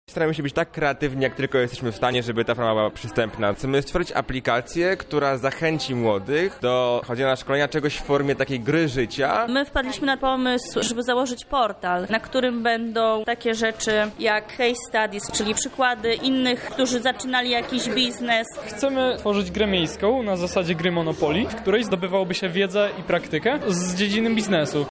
O swoich pomysłach na rozwiązanie tego problemu opowiadają uczestnicy.